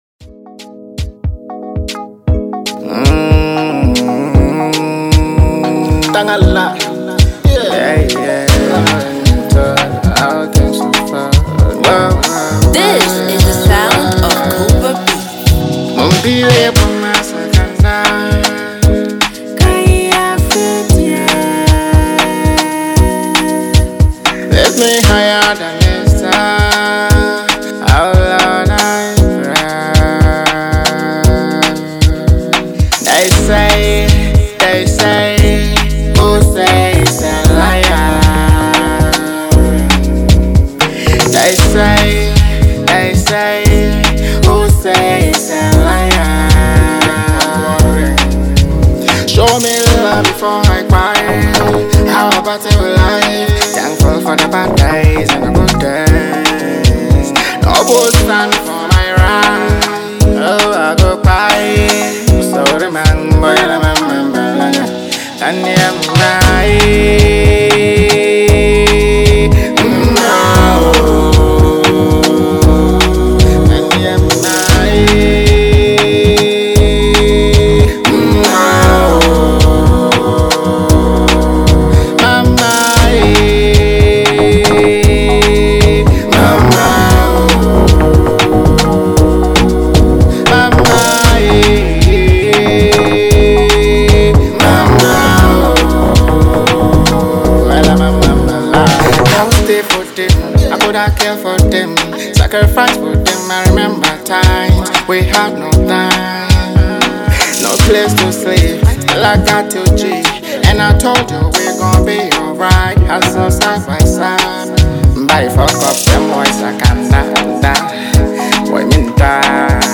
Genre: Highlife / Afrobeat